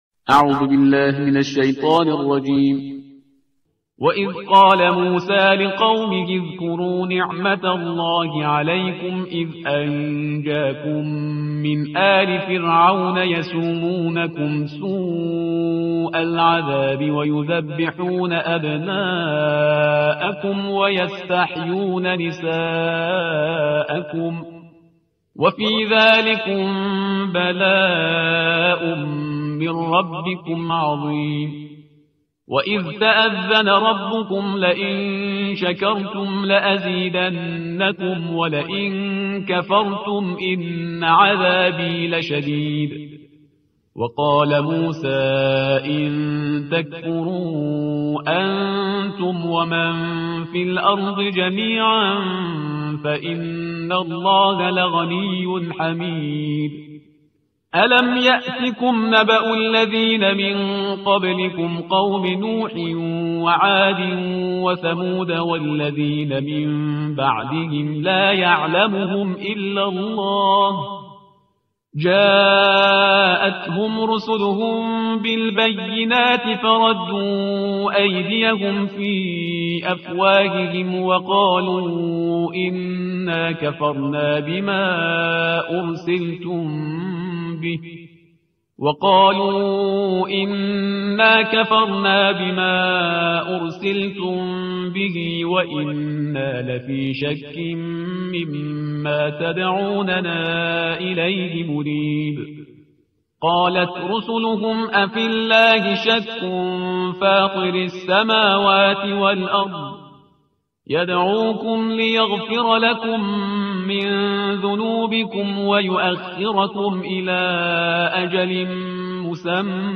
ترتیل صفحه 256 قرآن با صدای شهریار پرهیزگار